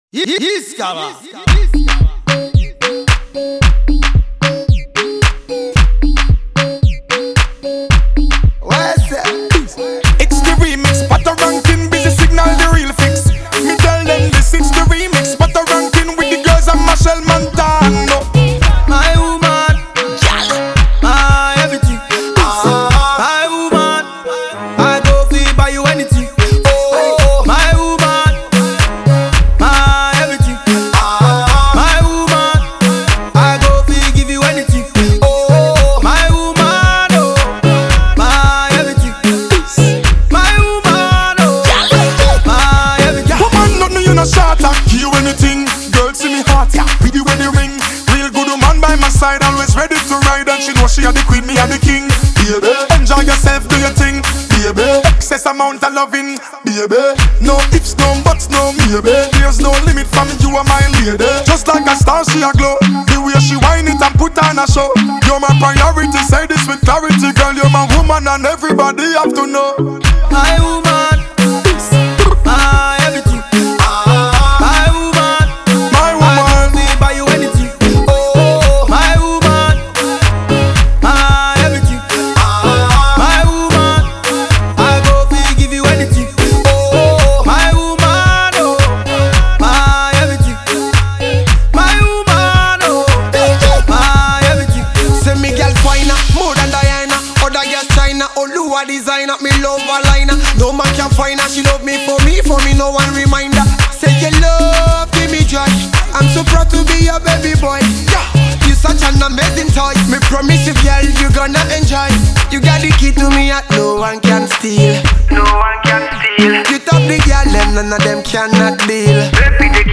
Home Naija Music Reggae/Dancehall